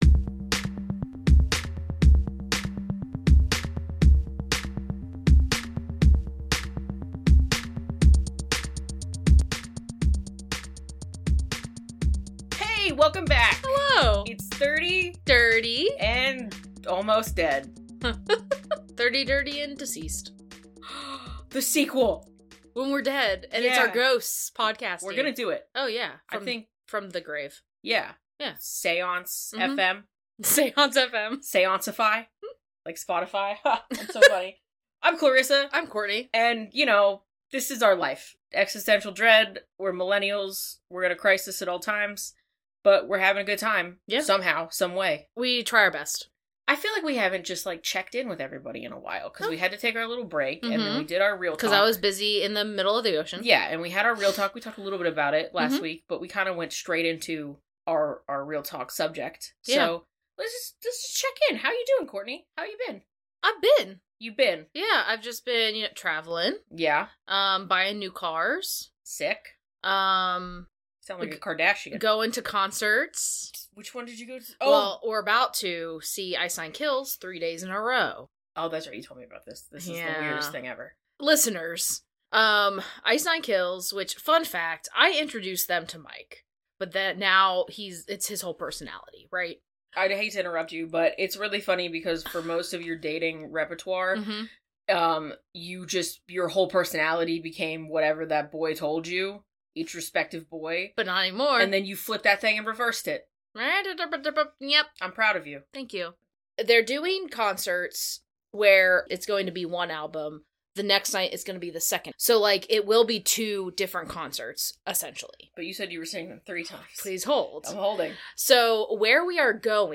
No ownership, monetary compensation, or other benefit is being gained through the mention of these songs, and the brief playing of edited clips is done for the purposes of commentary, education, and review, in line with Fair Use.